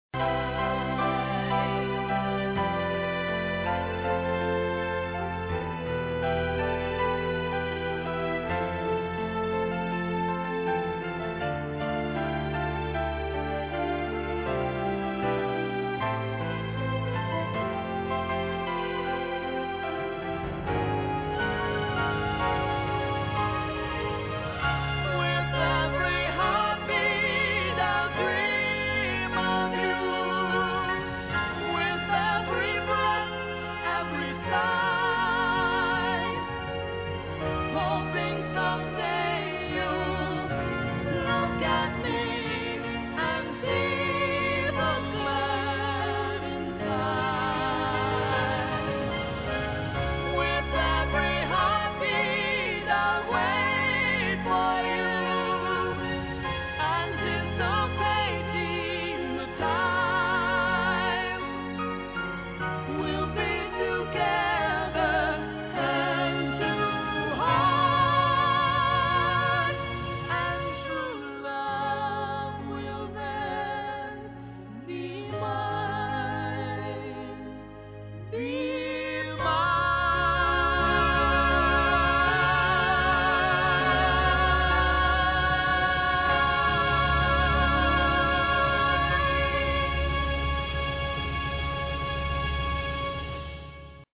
Accoustical Guitar
Background Vocals